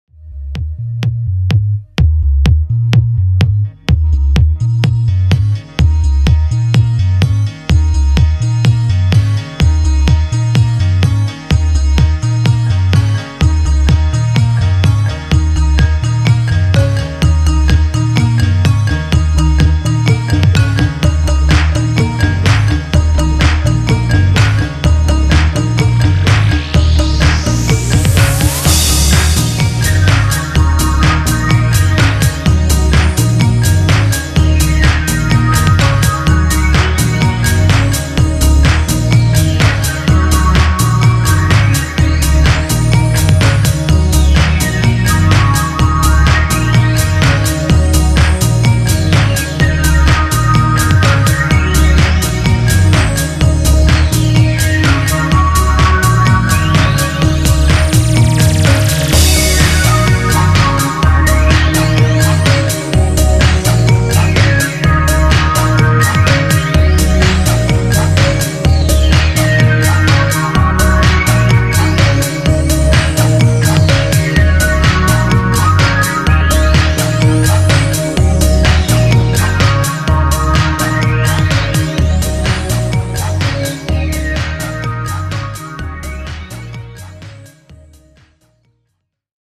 progressive house trance
progressive tribal house